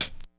Medicated Hat 19.wav